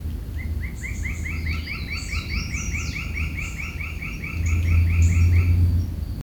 Carpintero Garganta Estriada (Dryocopus lineatus)
Nombre en inglés: Lineated Woodpecker
Localidad o área protegida: Parque Provincial Teyú Cuaré
Condición: Silvestre
Certeza: Observada, Vocalización Grabada
Carpintero-garganta-estriada_1.mp3